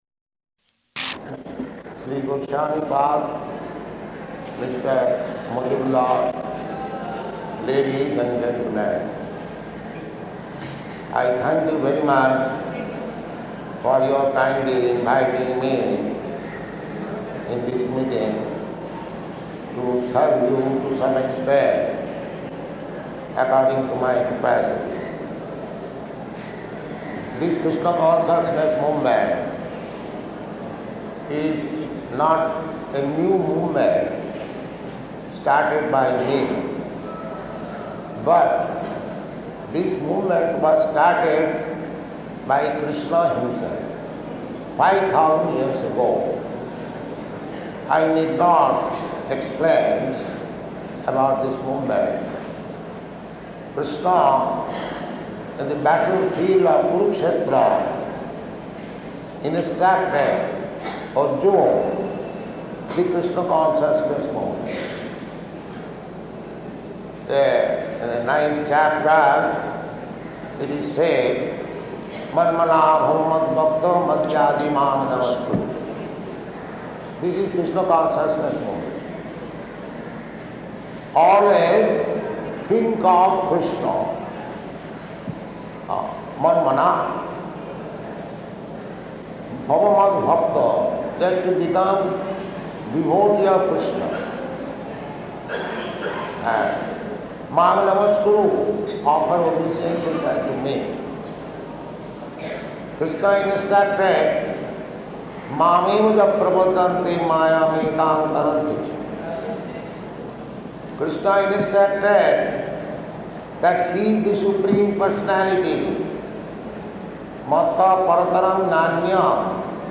Lecture at University
Type: Lectures and Addresses
Location: Calcutta